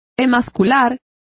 Complete with pronunciation of the translation of emasculate.